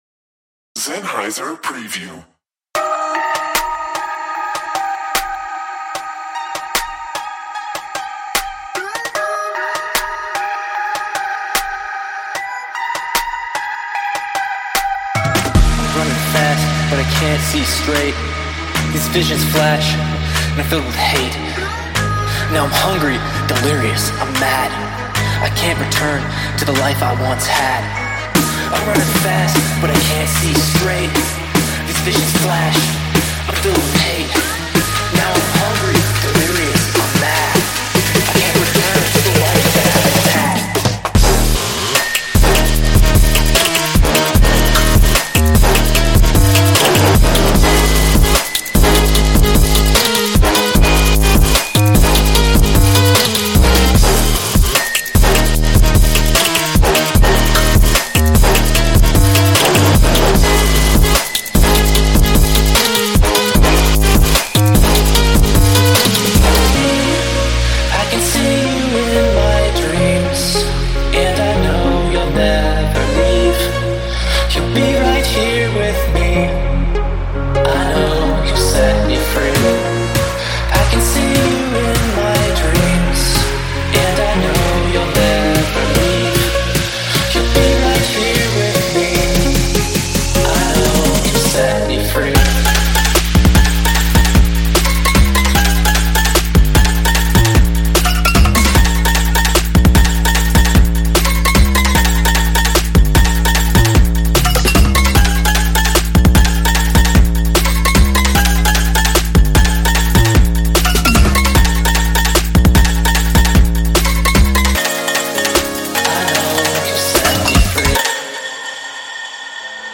Tempo - 150bpm